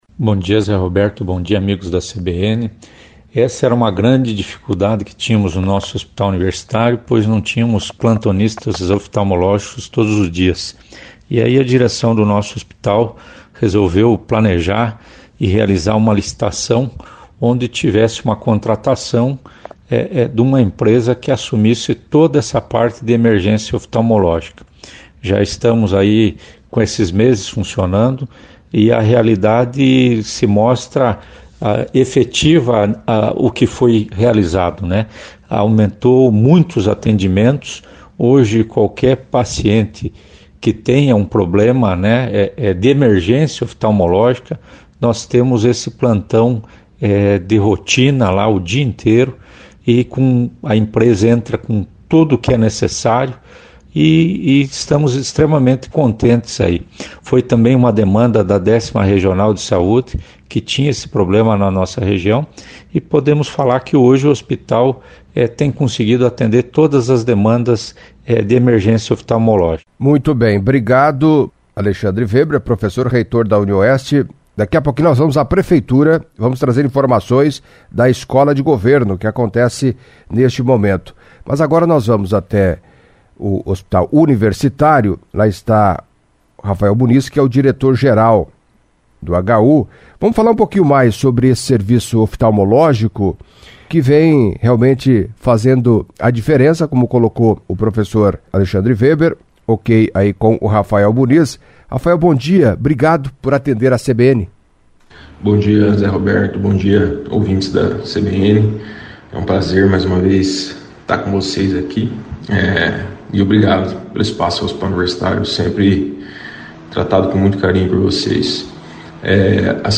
Em entrevista à CBN Cascavel nesta terça-feira (07)